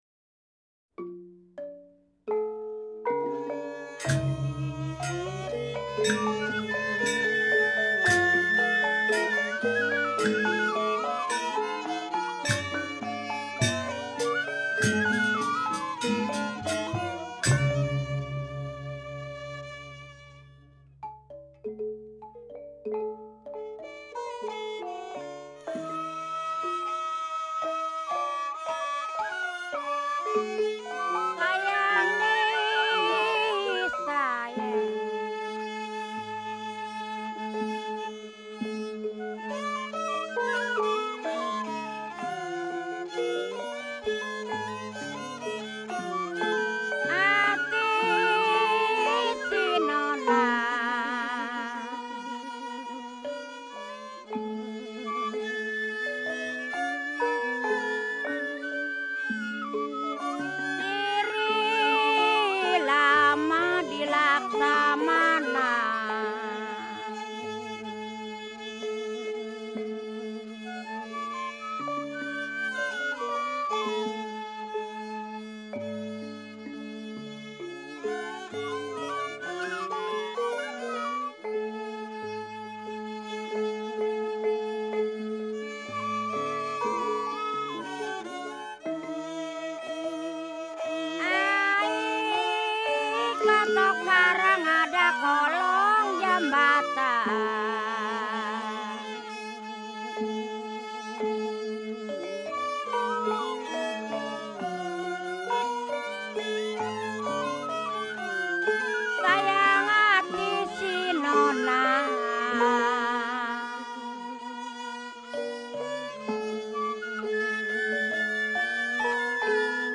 Gambang Kromong
Penyanyi
Terompet
Suling
Goong dan Kempul